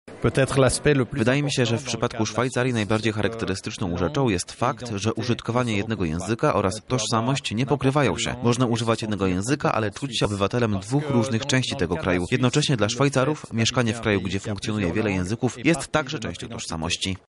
– tłumaczy Martin Michelet, Minister Ambasady Szwajcarskiej w Warszawie.